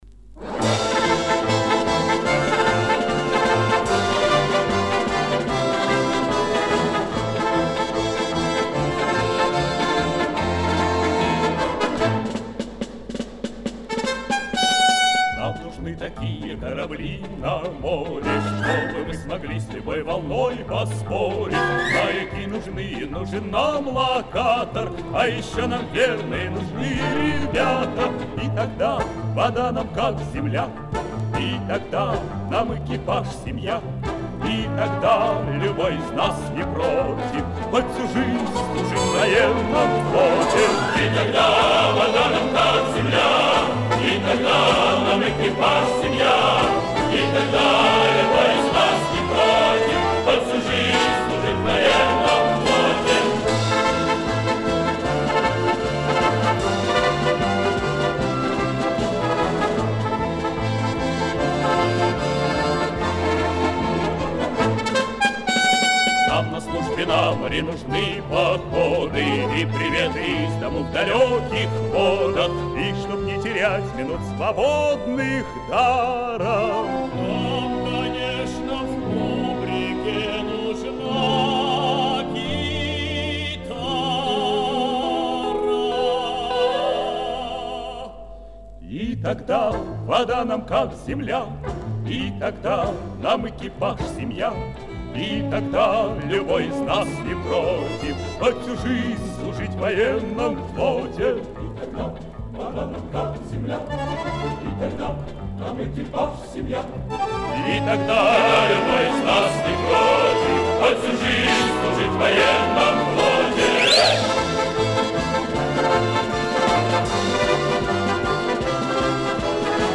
Повышение качества.